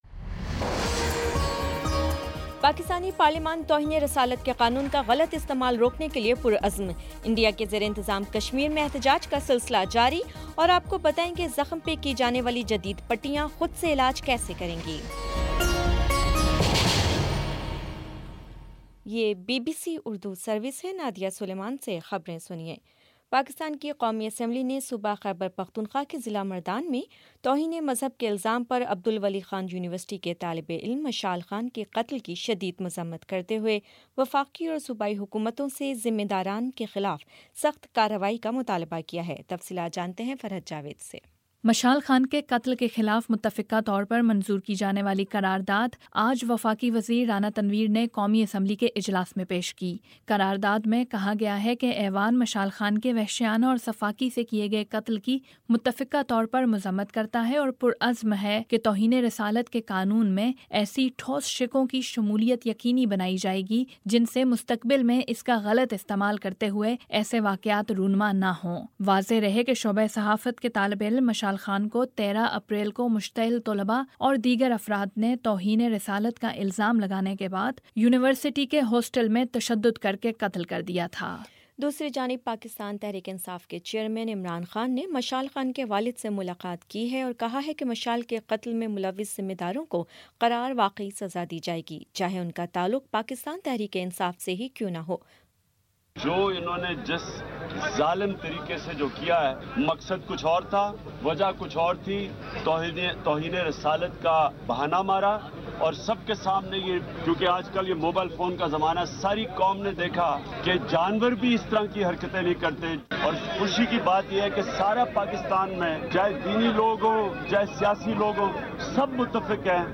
اپریل 18 : شام سات بجے کا نیوز بُلیٹن